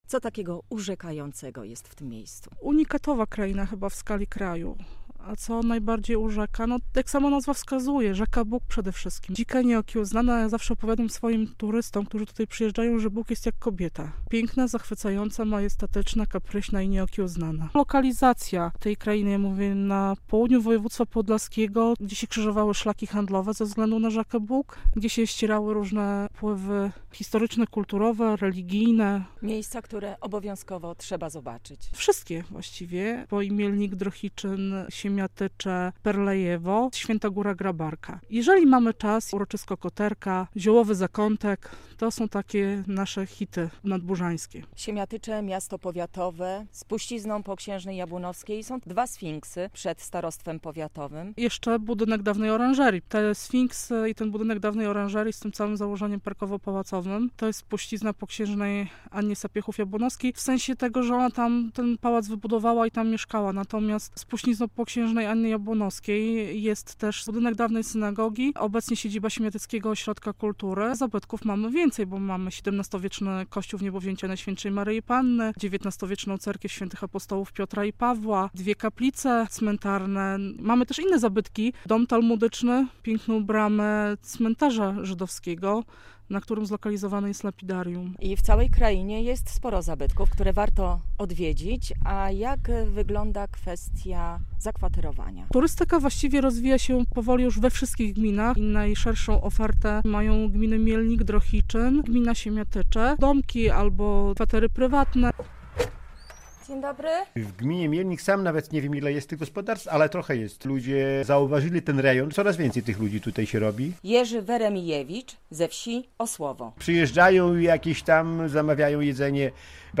Co warto zobaczyć w Nadbużańskiej Krainie?- relacja